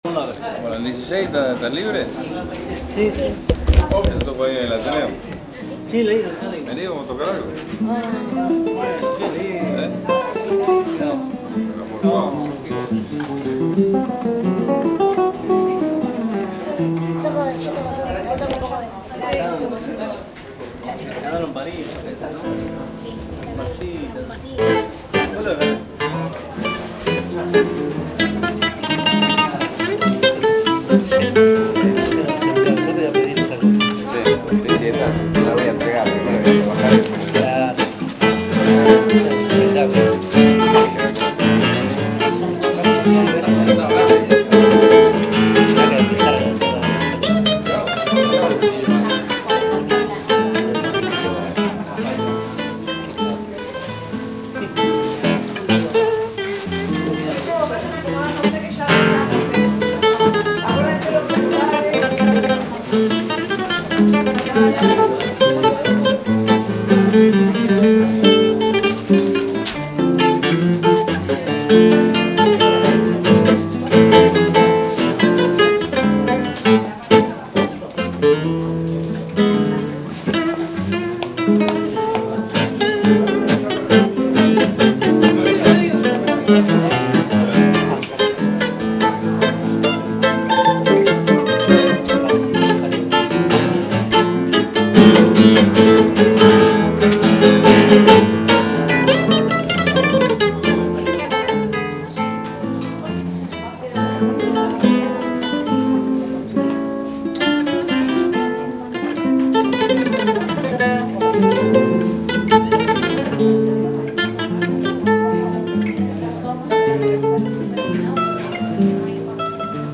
guitarras